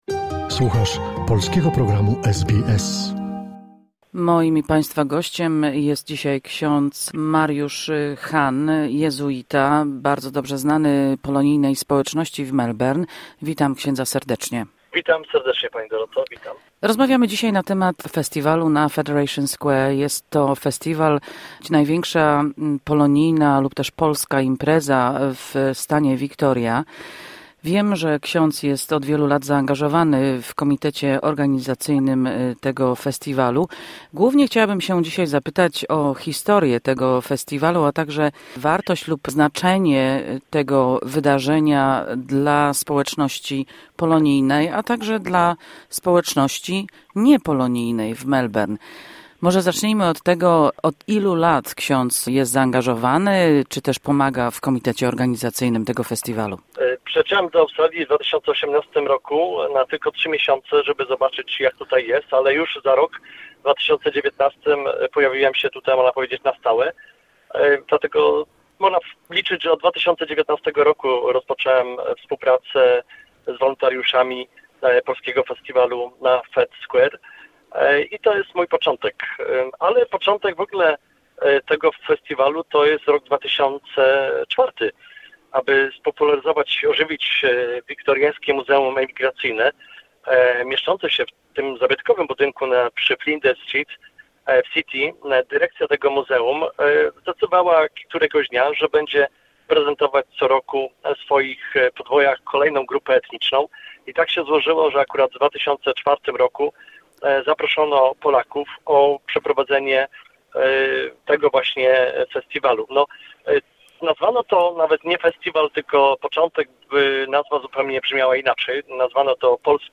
To rozmowa o historii festiwalu, o jego znaczeniu dla społeczności, o ludziach, którzy przyczynili się do jego powstania i trwania, a także o przyszłości tego największego polskiego festiwalu w australijskim stanie Wiktoria.